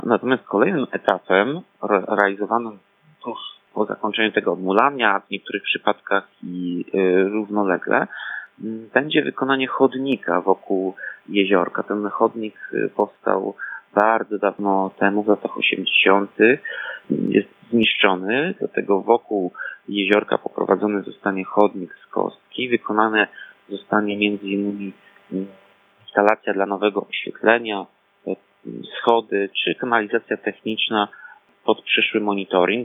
Tomasz Andrukiewicz, prezydent Ełku mówi, że roboty zaczną się od akwenu.